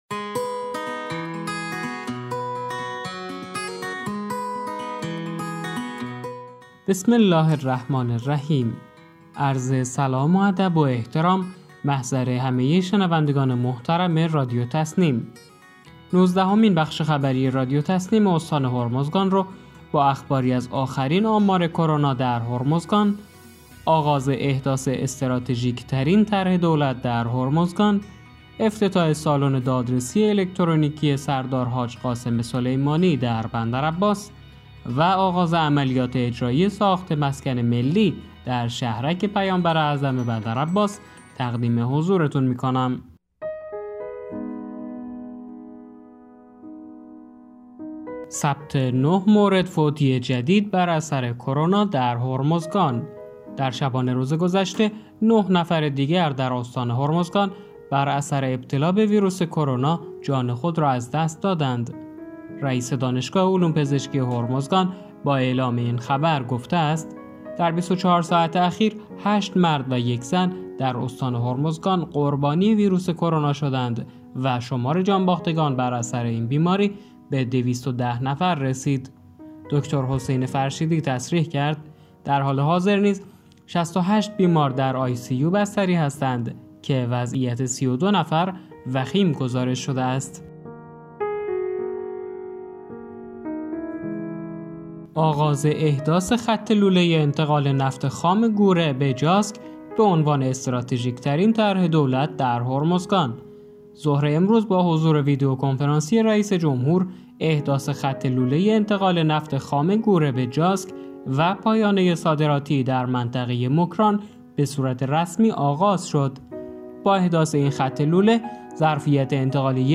به گزارش خبرگزاری تسنیم از بندرعباس، نوزدهمین بخش خبری رادیو تسنیم استان هرمزگان با اخباری از آخرین آمار کرونا در هرمزگان، آغاز احداث استراتژیک‌ترین طرح دولت در هرمزگان، افتتاح سالن دادرسی الکترونیک سردار حاج قاسم سلیمانی در بندرعباس و آغاز عملیات اجرایی ساخت مسکن ملی در شهرک پیامبر اعظم بندرعباس منتشر شد.